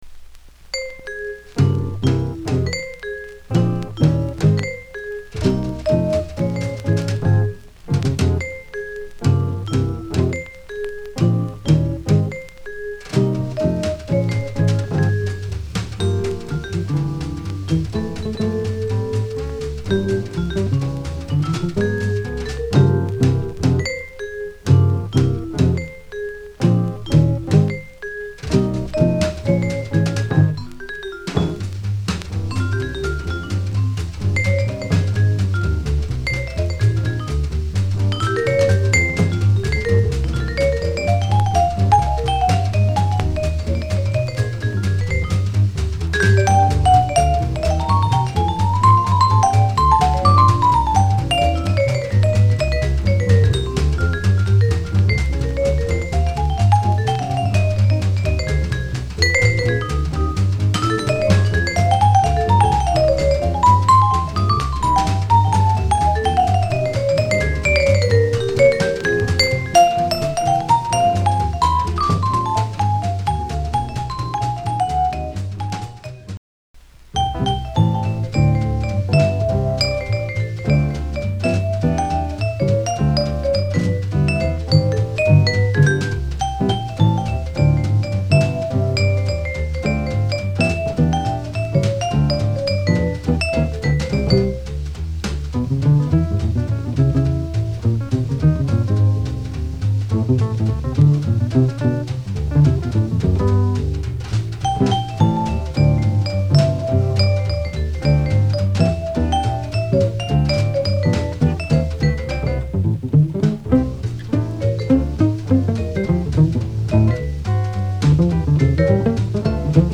チェロ兼ベーシスト
B面に浅い小キズありますがモノラルカートリッジ使用で再生に影響ありませんでした。